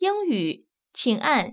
ivr-for_english_press.wav